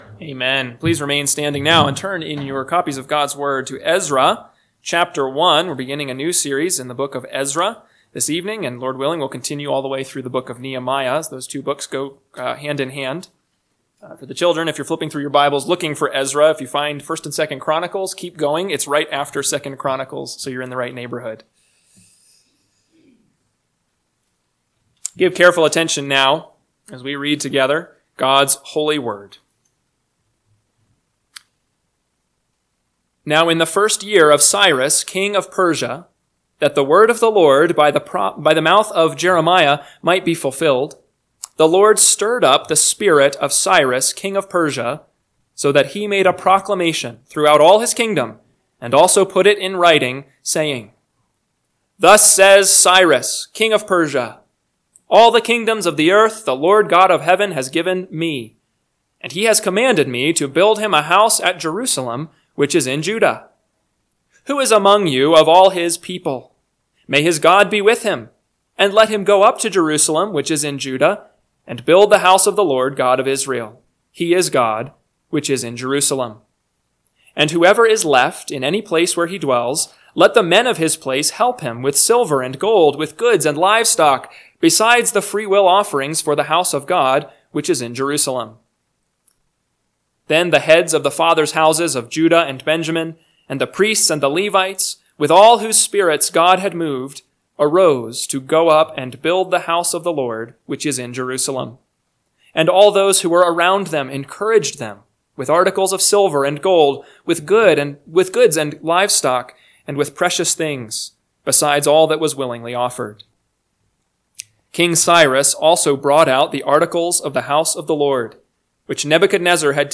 PM Sermon – 2/16/2025 – Ezra 1 – Northwoods Sermons